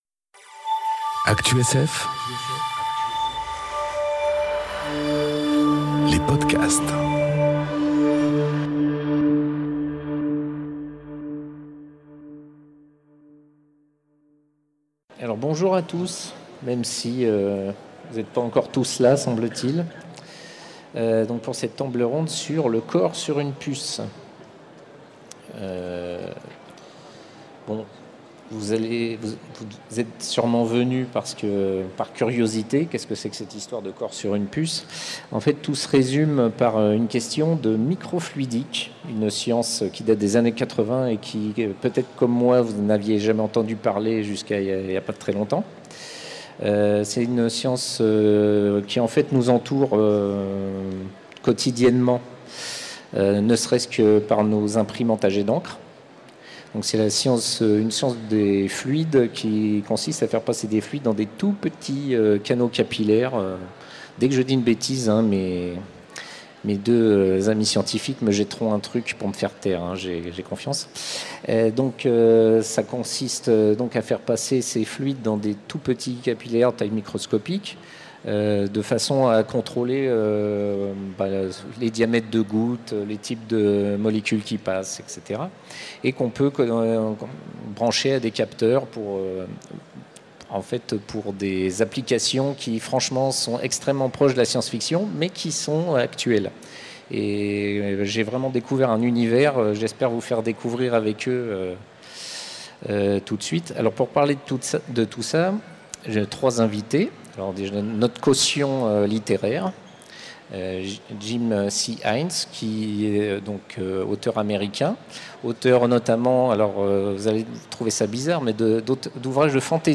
Conférence Le corps sur une puce enregistrée aux Utopiales 2018